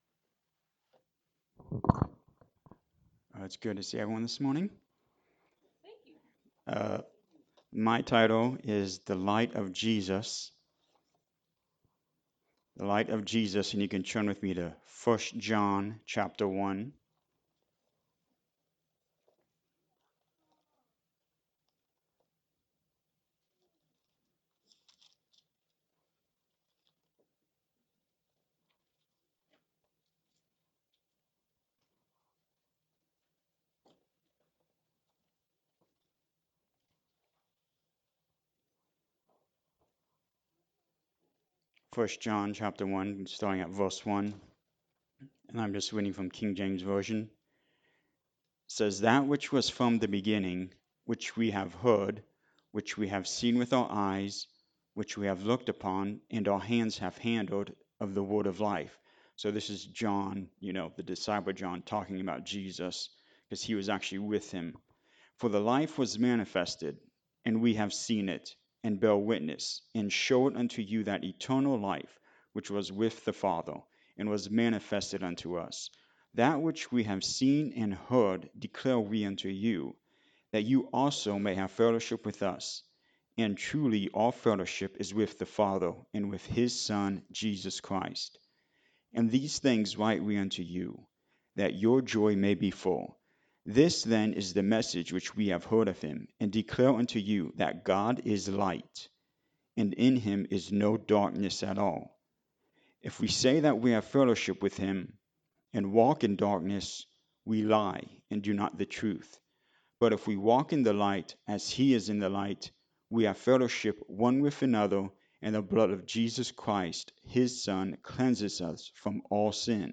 Today we read most of the first chapter together as a congregation, and will continue to read through his book for the next few weeks.
Sunday-Sermon-for-June-8-2025.mp3